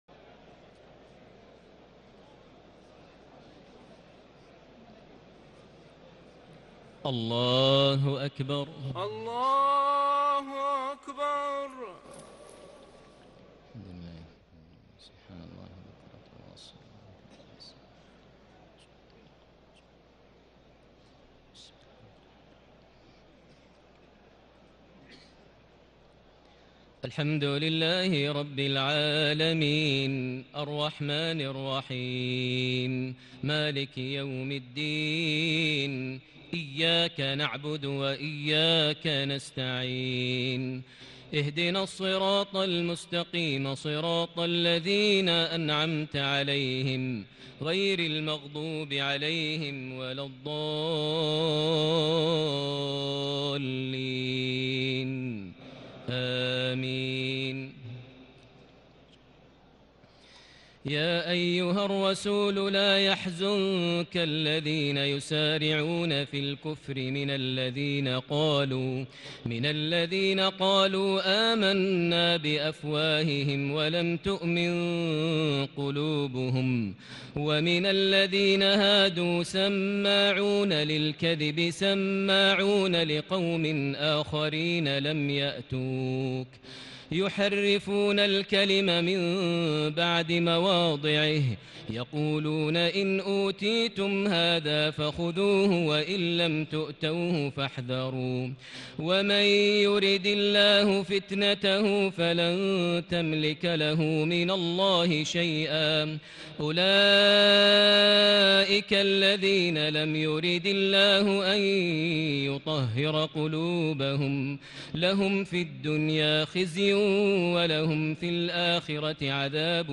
تهجد ليلة 26 رمضان 1440هـ من سورة المائدة (41-81) Tahajjud 26 st night Ramadan 1440H from Surah AlMa'idah > تراويح الحرم المكي عام 1440 🕋 > التراويح - تلاوات الحرمين